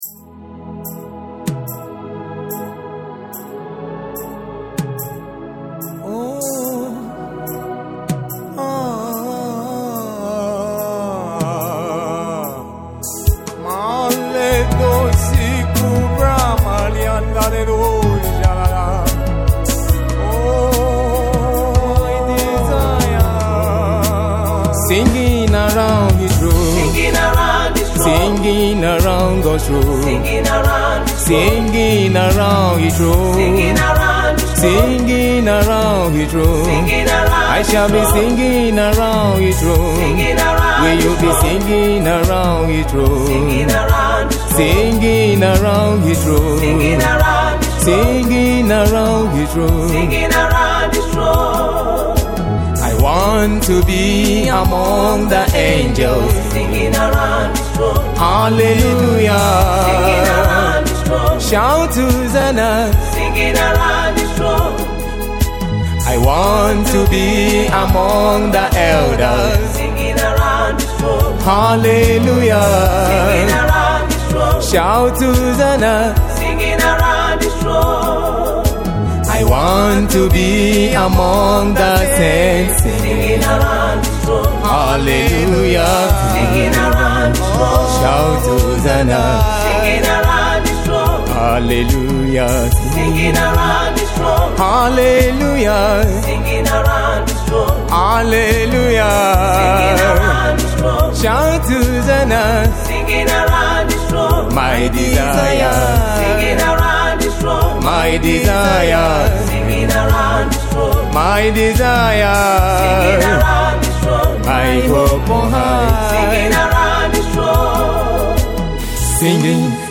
Soulful Anthem
In the ever-evolving world of gospel music